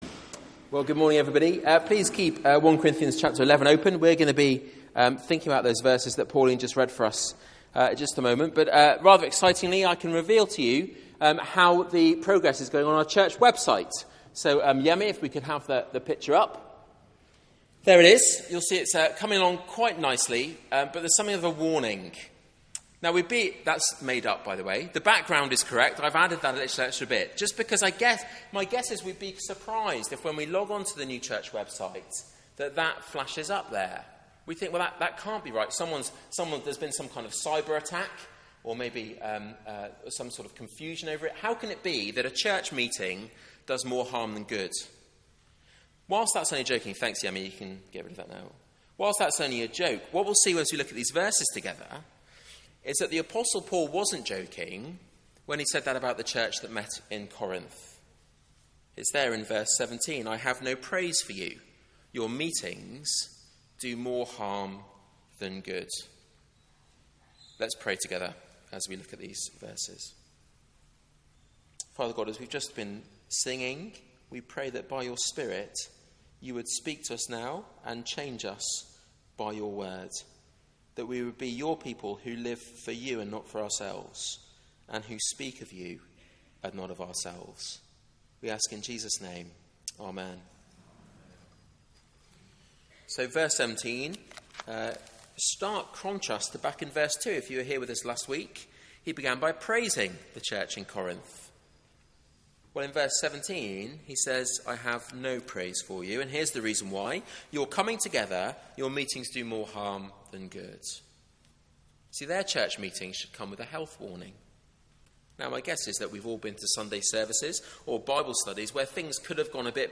Media for 9:15am Service on Sun 28th Jun 2015 09:15
Series: A CHURCH WITH ISSUES Theme: Recognising the body Sermon